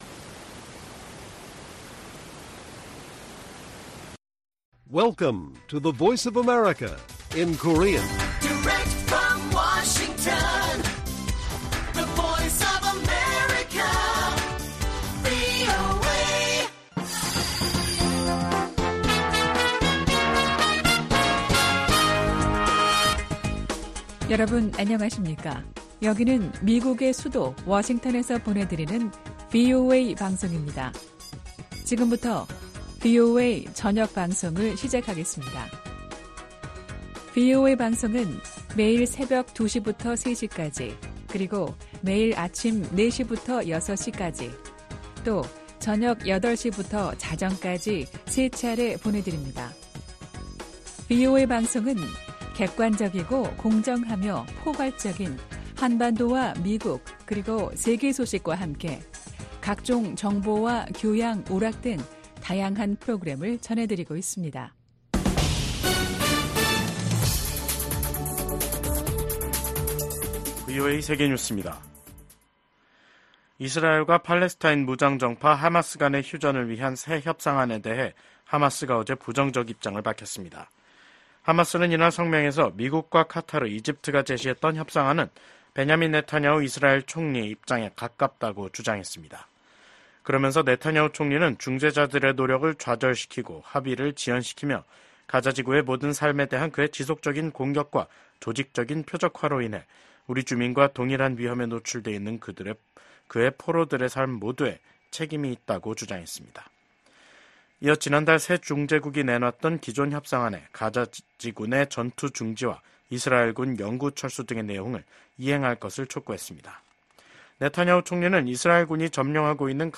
VOA 한국어 간판 뉴스 프로그램 '뉴스 투데이', 2024년 8월 19일 1부 방송입니다. 미국과 한국, 일본이 캠프 데이비드 정상회의 1주년을 맞아 공동성명을 발표했습니다. 북한이 올해 말부터 제한적으로 외국인 관광을 재개할 것으로 알려진 가운데 미국은 자국민 방북을 절대 불허한다는 방침을 거듭 확인했습니다. 미국 정부는 윤석열 한국 대통령이 발표한 ‘8.15 통일 독트린’에 대한 지지 입장을 밝혔습니다.